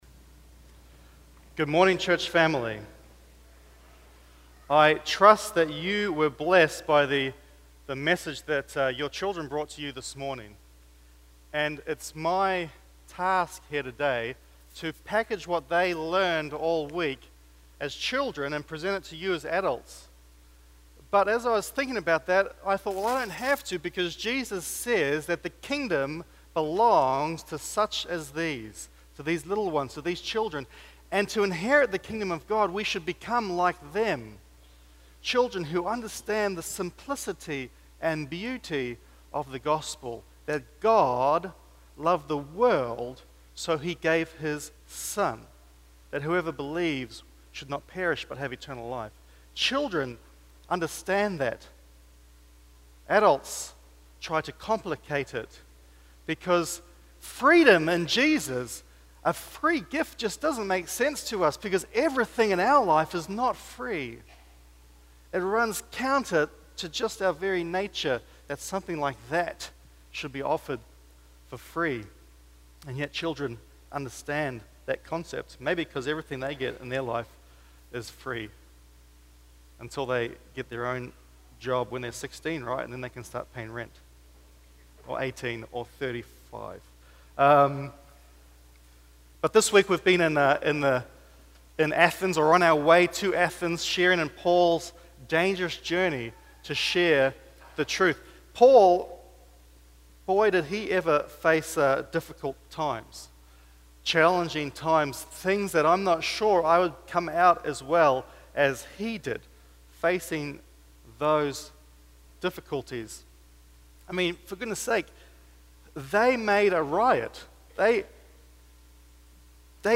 Vallejo Drive Seventh-day Adventist Church - Media Entry